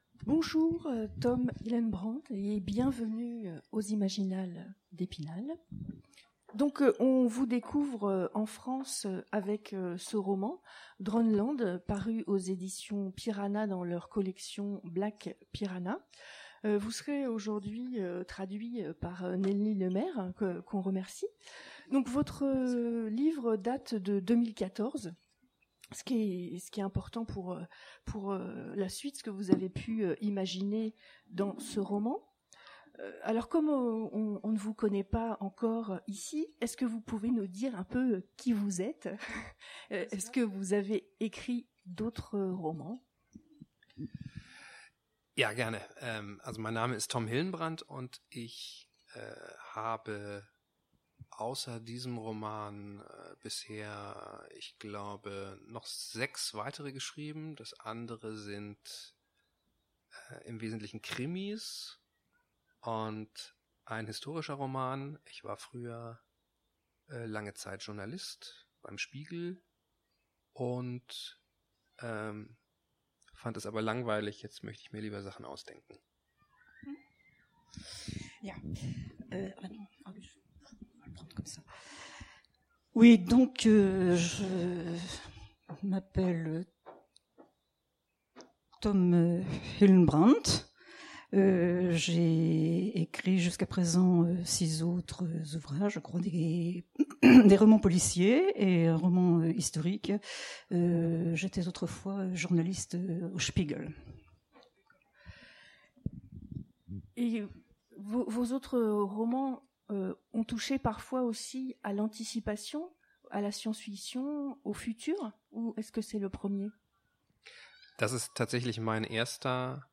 Imaginales 2017 : Entretien
Mots-clés Rencontre avec un auteur Conférence Partager cet article